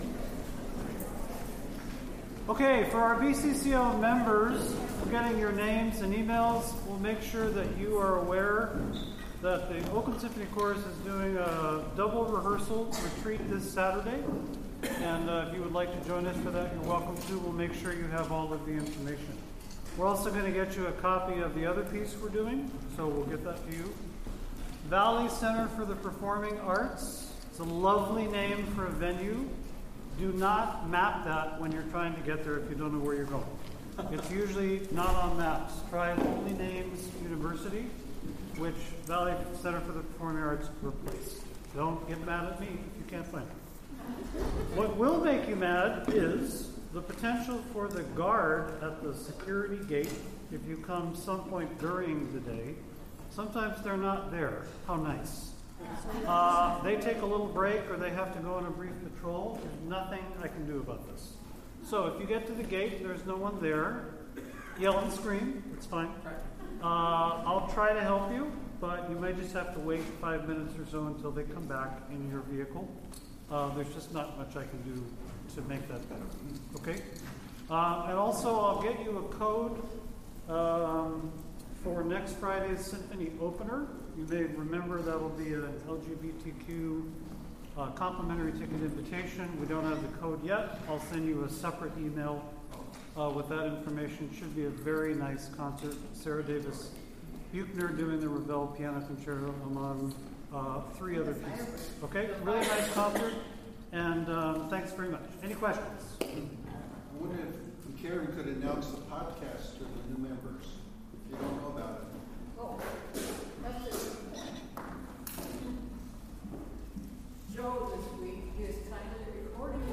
OSC Rehearsal, Wednesday, October 8, 2025
Vocal warm-ups.